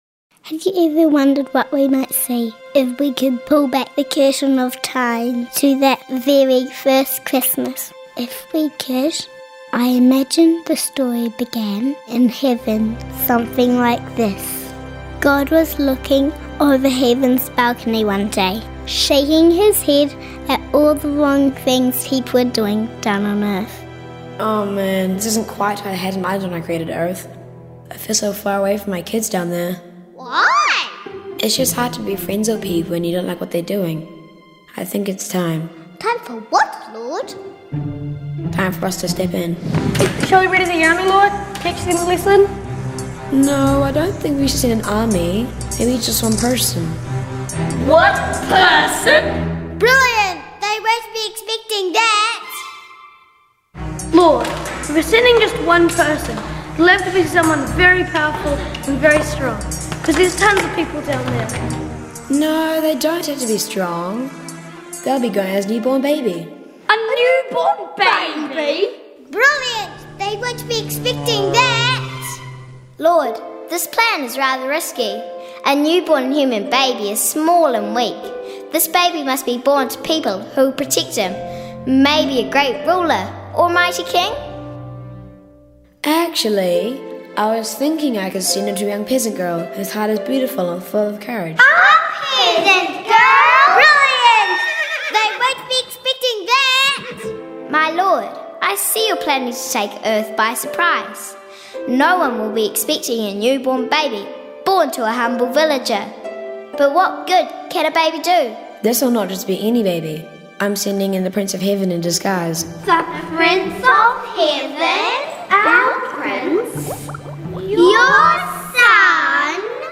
Unexpected Christmas’. played by Australian children (Watch the video [first 2minutes and 34 seconds]).